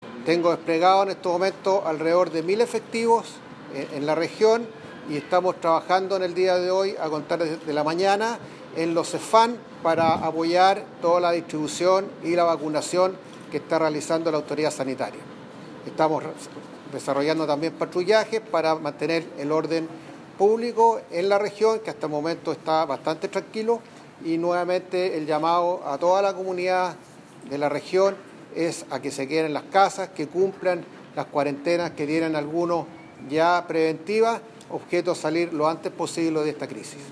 El Contraalmirante y Jefe de la Defensa Nacional para el Biobío, Carlos Huber, junto al Intendente Sergio Giacaman, y el Seremi de Salud, Héctor Muñoz, realizaron esta mañana un punto de prensa tras el Comité Operativo de Emergencia con el fin de actualizar la situación local con respecto al  brote de Covid-19.